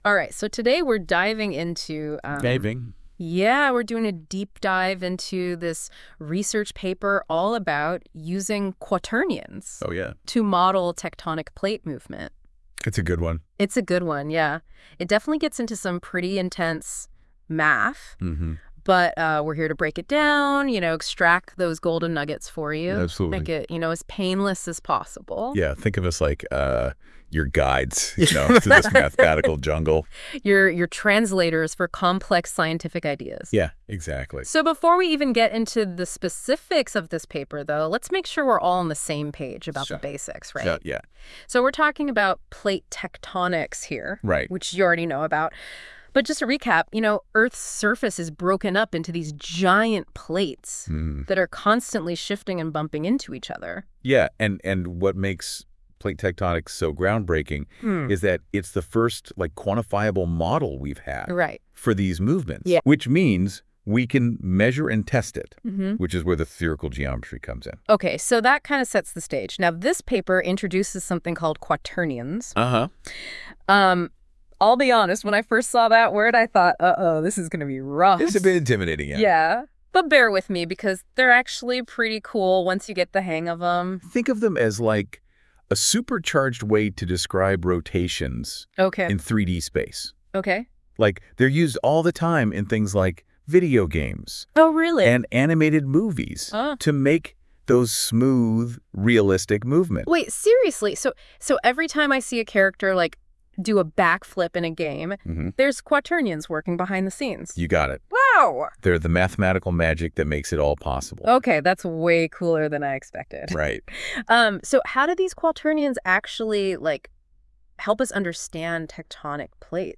Audio AI-generated[^1] audio summary